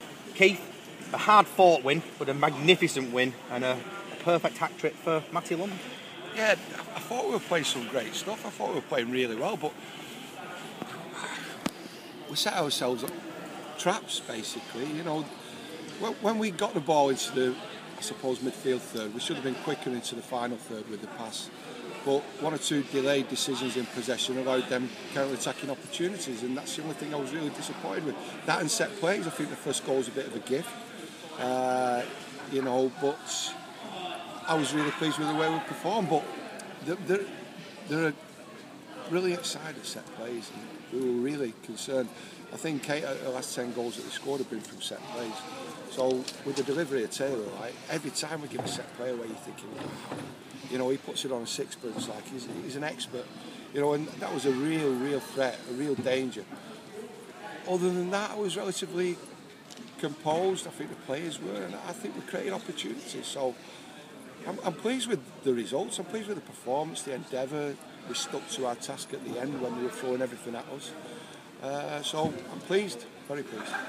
Rochdale manager Keith Hill gives his post-match interview following a 2-3 win at League One rivals Northampton Town.